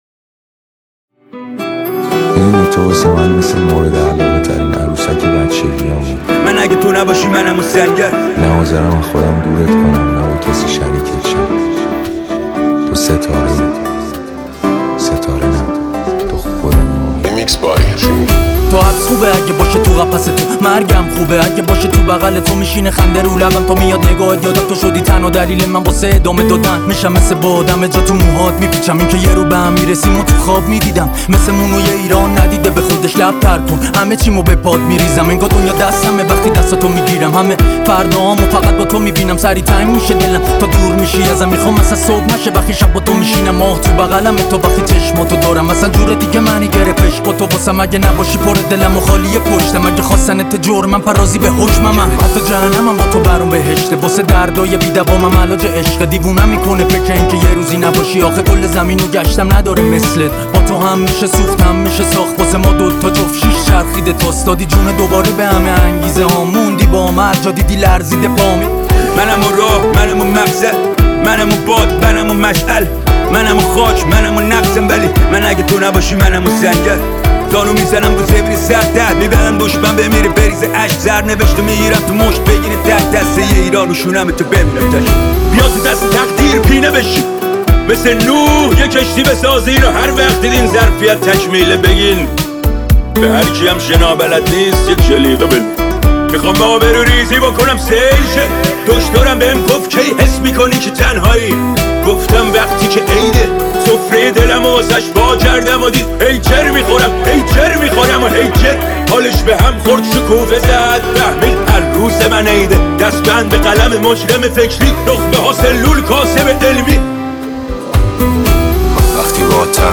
دانلود ریمیکس جدید رپ